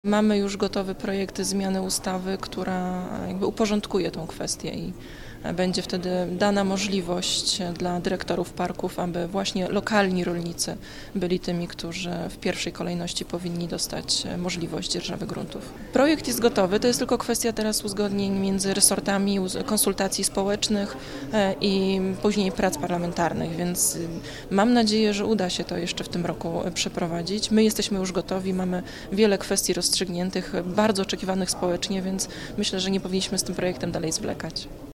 Dziś z rolnikami spotkała się sekretarz stanu w Ministerstwie Środowiska, Główny Konserwator Przyrody Małgorzata Joanna Golińska. W Lubuskim Urzędzie Wojewódzkim uspokajała rolników, informując ich o nowym rozwiązaniu.